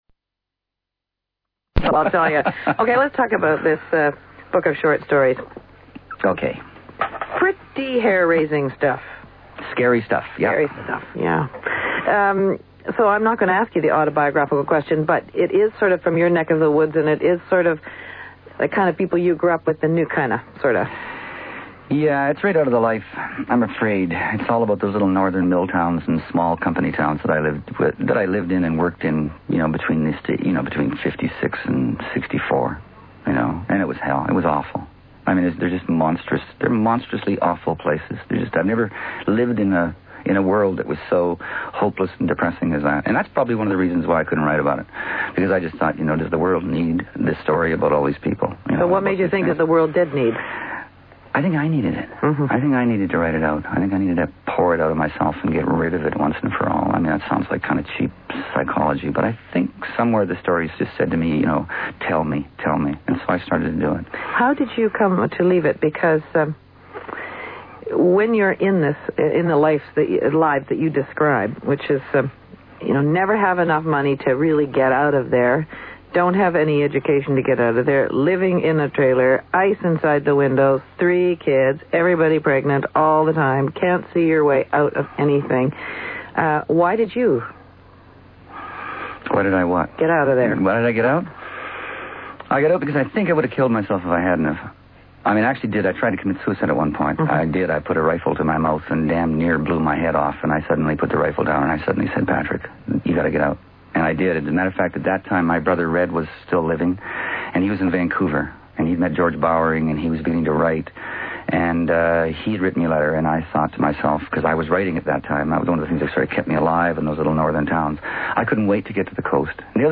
Vicki Gabereau interviews Canadian poet Patrick Lane
Canadian broadcaster Vicki Gabereau was host of Gabereau, a two-hour daily interview show that ran on CBC (Canadian Broadcasting Corporation) radio from 1985 to 1997.
Among them was the Canadian poet Patrick Lane who described what his life was like in the early 1960s in a small northern BC mill town. He also reads from some of his short stories.